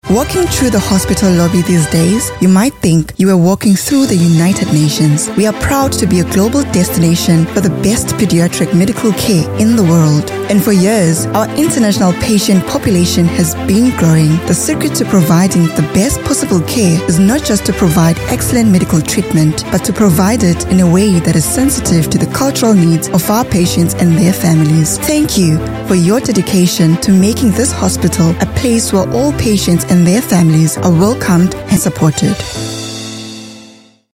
articulate, authoritative, bright, commercial, confident, conversational, energetic, informative
Her voice has a unique or rare natural warmth, that can capture the attention of the audience.
Medium Sell